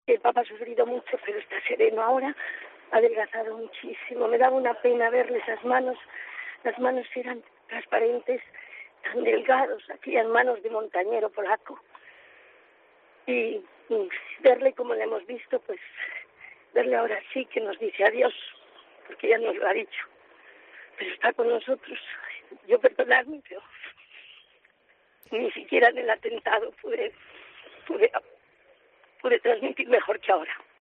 Así se emocionaba Paloma Gómez Borrero al decir adiós a San Juan Pablo II